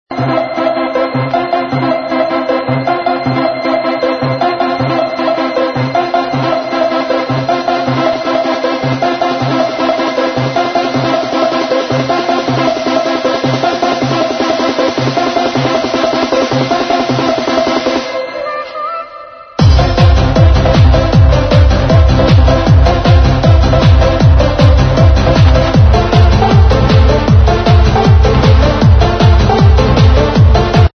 classic hard-trance 95-98?